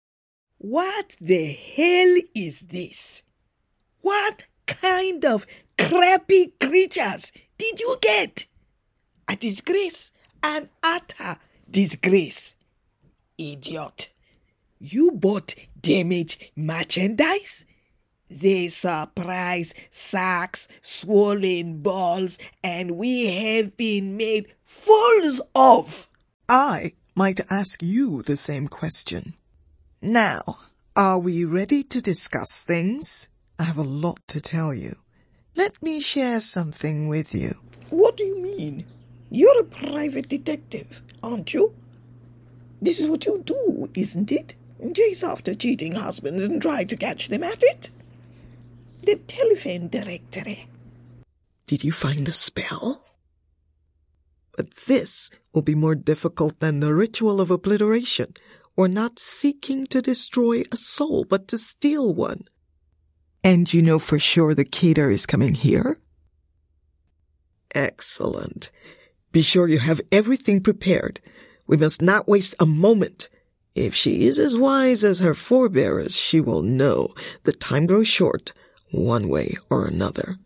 VOICE REEL
British Actress and Voice Over Artist.